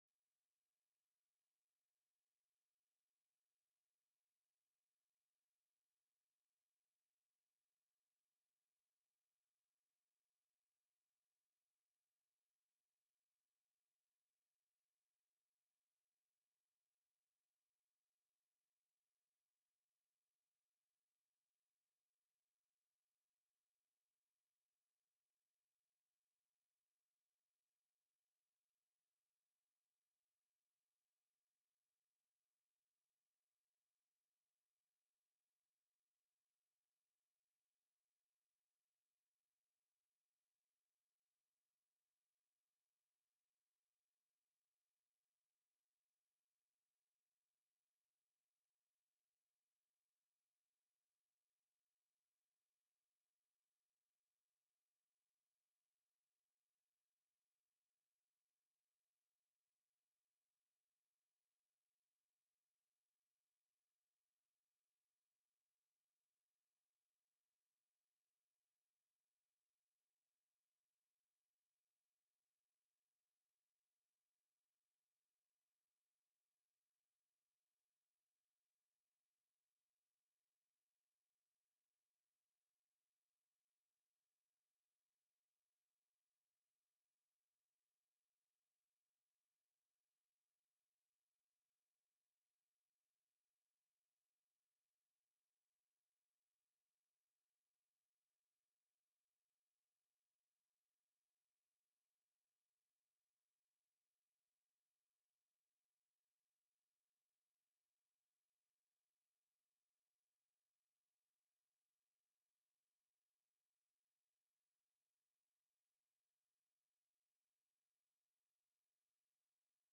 The audio recordings are captured by our records offices as the official record of the meeting and will have more accurate timestamps.
Co-Chair Hoffman called the Senate Finance Committee meeting to order at 9:01 a.m.